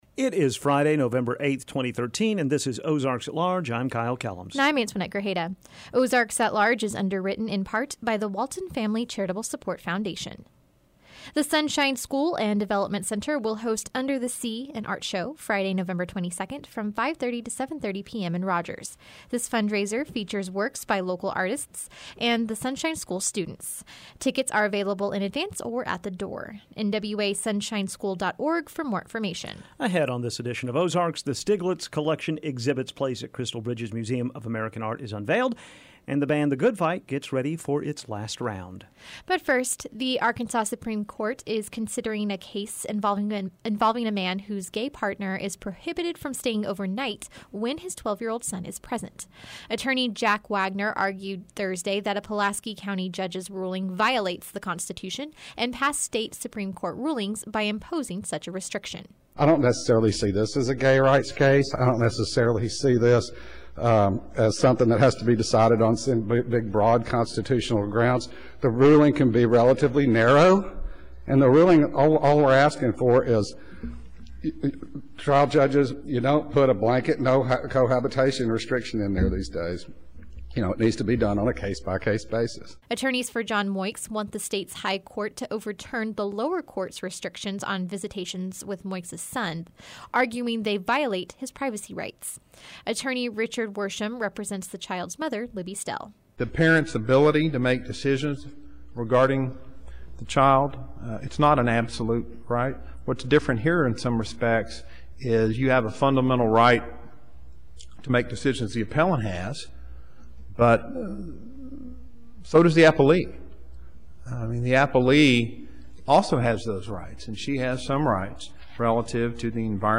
the band A Good Fight sits inside the Firmin Garner Performance Studio to perform and give their last radio interview before they split up.